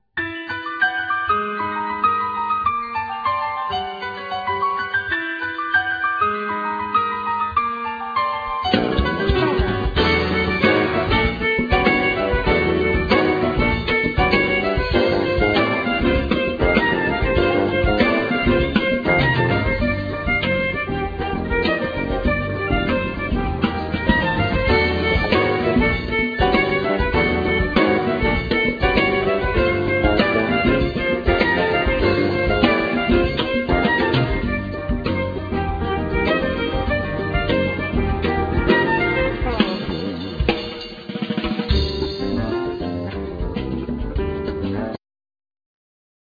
Violin
Piano,Keyboards
Bass
Drums,Percussions